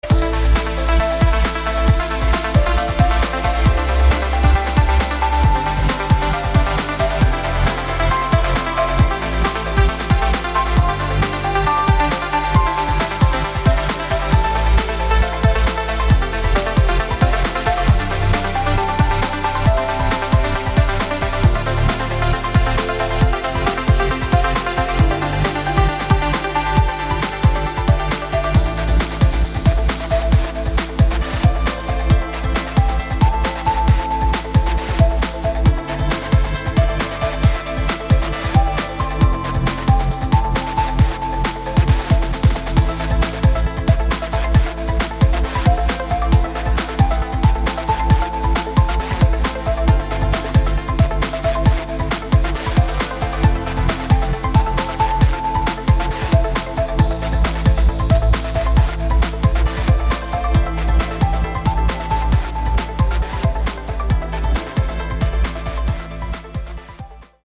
красивые
dance
Electronic
электронная музыка
спокойные
без слов
Downtempo
Lounge
Chill